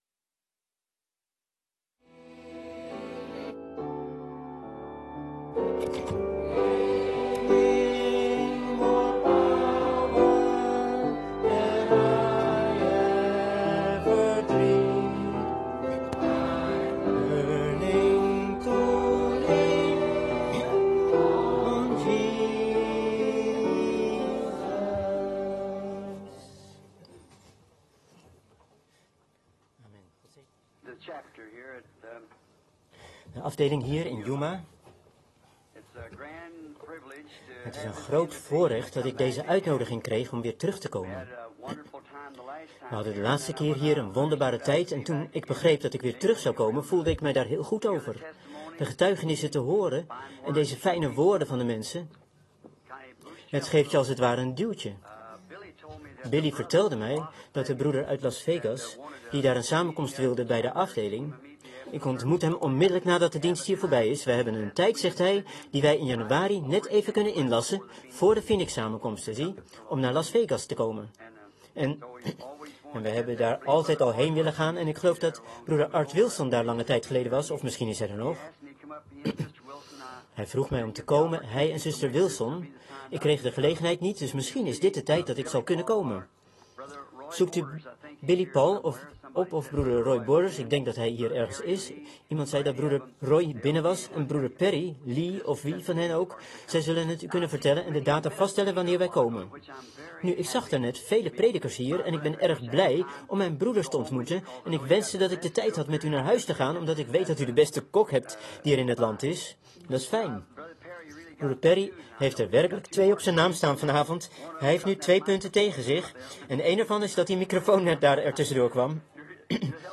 De vertaalde prediking "The rapture" door William Marrion Branham gehouden in Ramada inn, Yuma, Arizona, USA, 's avonds op zaterdag 04 december 1965